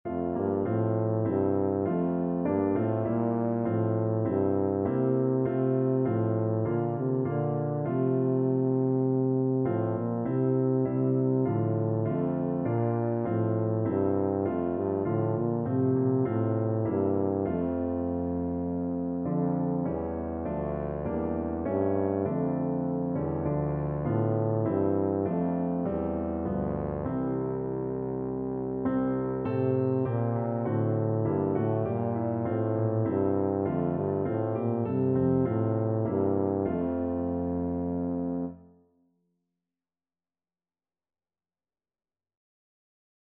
Christmas Christmas Tuba Sheet Music It Came Upon the Midnight Clear
Tuba
F major (Sounding Pitch) (View more F major Music for Tuba )
4/4 (View more 4/4 Music)
C3-D4
Classical (View more Classical Tuba Music)